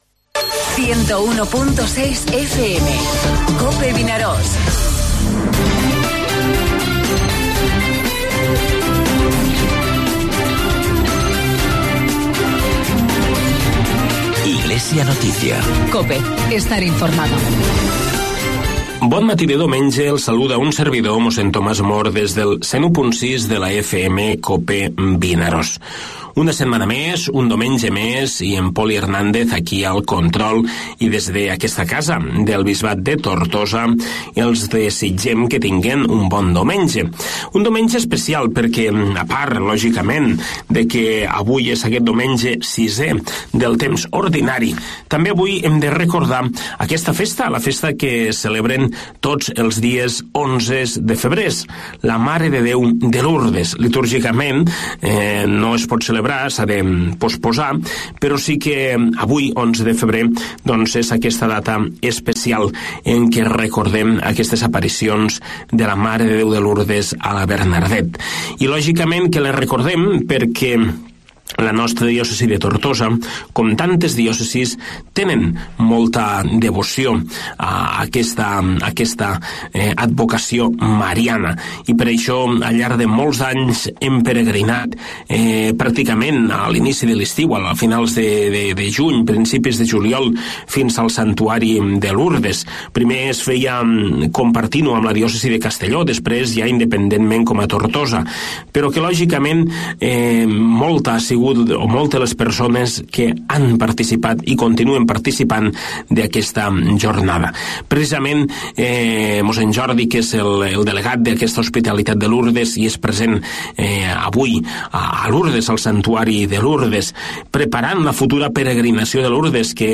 AUDIO: Espai informatiu del Bisbat de Tortosa, tots els diumenges de 9:45 a 10 hores.